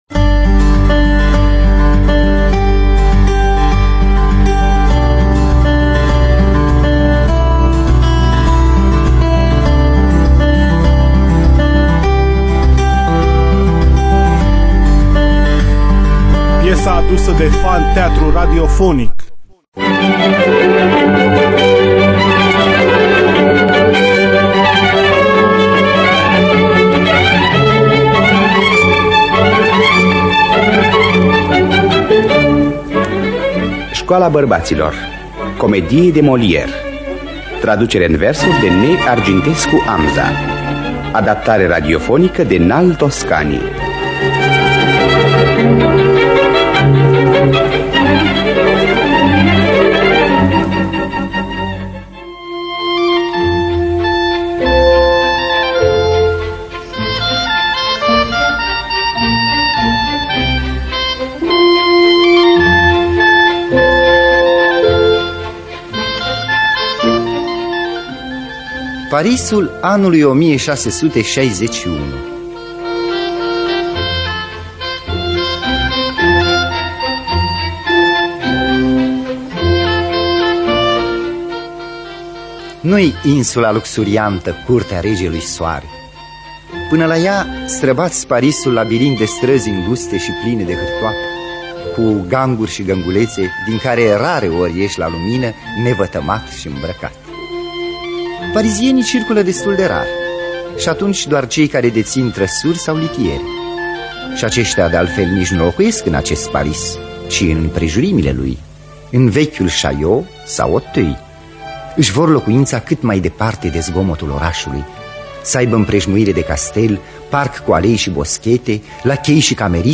Adaptarea radiofonică de N. Al. Toscani.